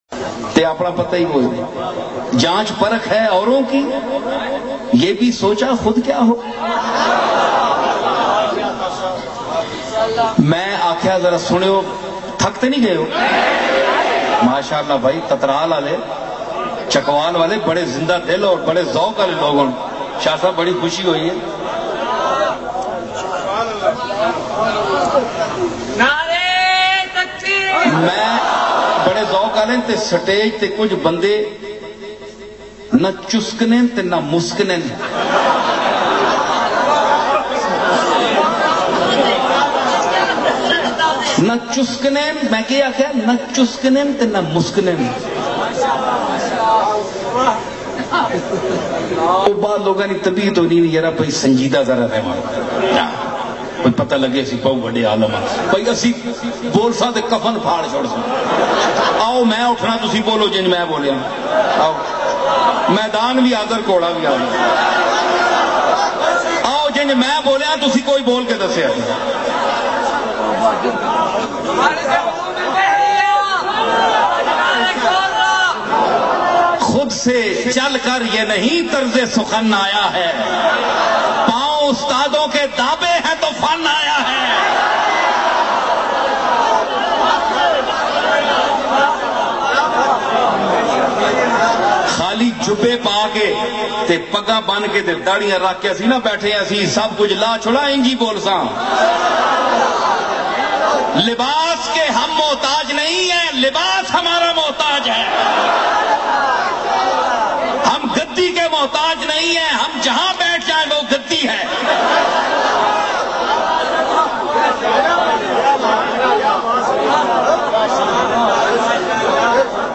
emotional byan